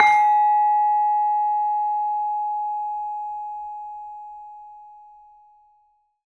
LAMEL G#4 -R.wav